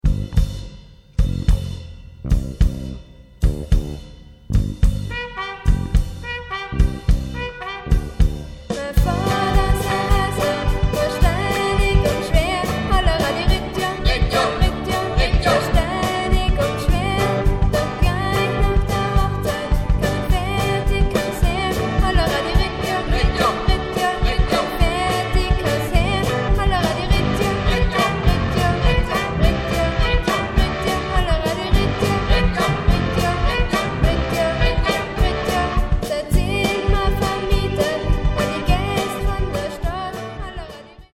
(Schüler der Hauptschule Laabental)